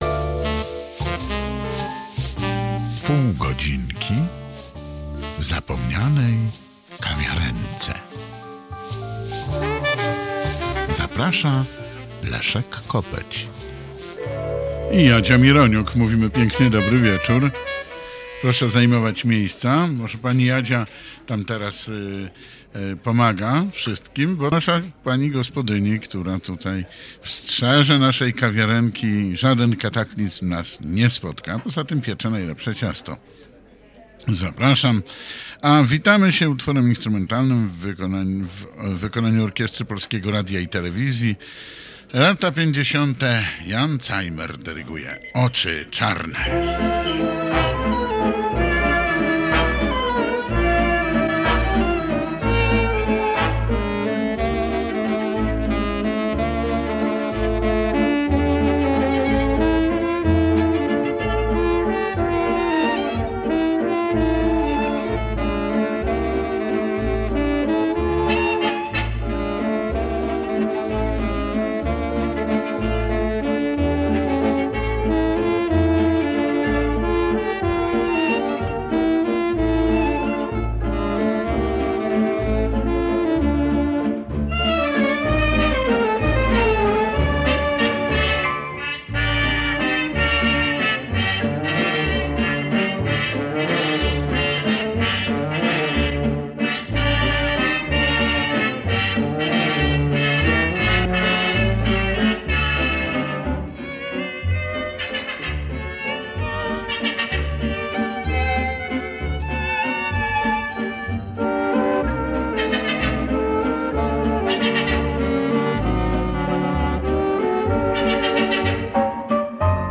Prezentowane są polskie piosenki z lat: 30,50, 60 ubiegłego stulecia.